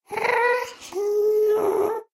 moan6.mp3